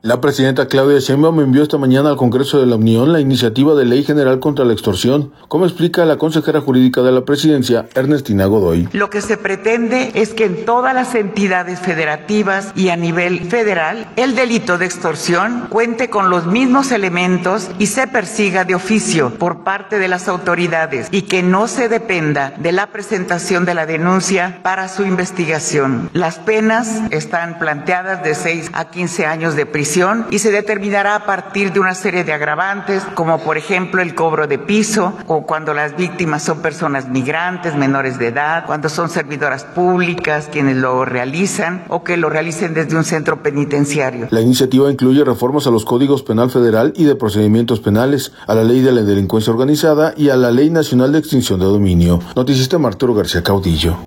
La presidenta Claudia Sheinbaum envió esta mañana al Congreso de la Unión, la iniciativa de Ley General contra la Extorsión, como explica la consejera jurídica de la Presidencia, Ernestina Godoy.